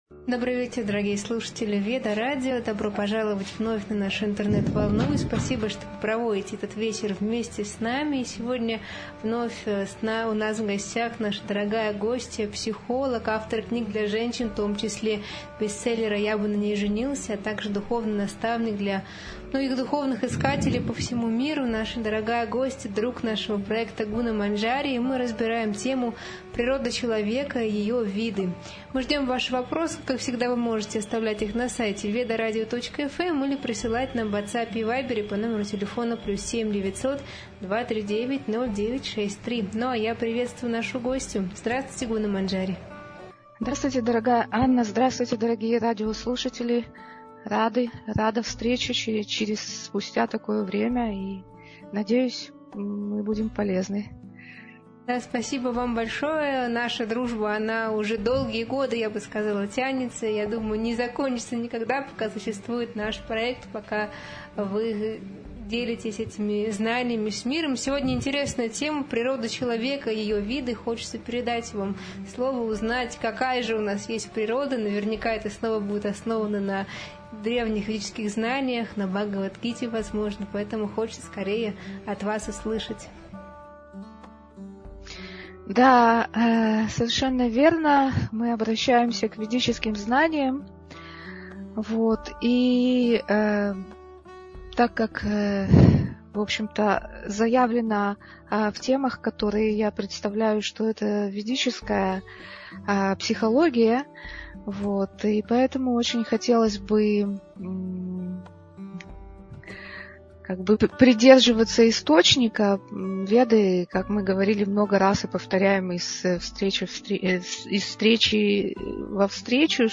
В прямом эфире Веда-радио
Обсуждаются гуны материальной природы, кастовая система, качества брахмана, роль наставника и пути саморазвития. Беседа дает практическое понимание, как сохранить чистоту, мудрость и внутреннюю свободу в современном мире.